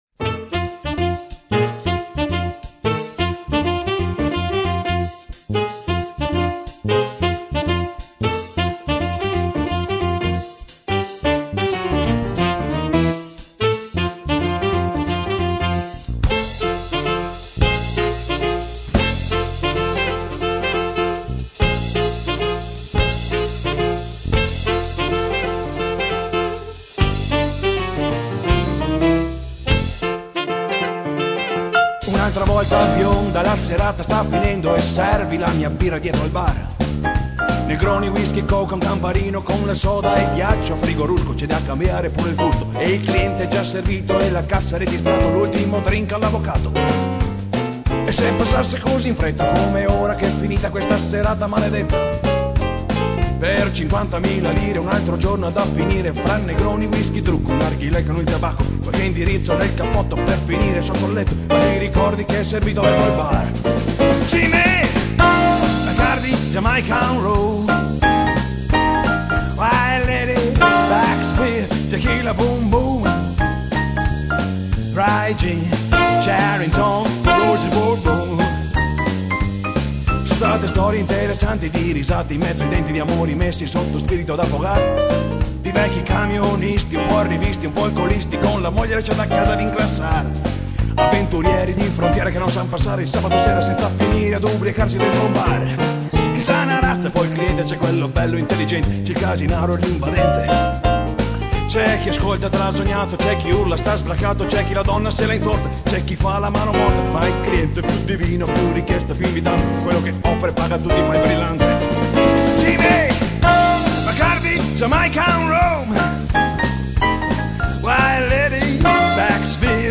accompagnandosi con la con chitarra acustica
batteria
pianoforte
basso
violino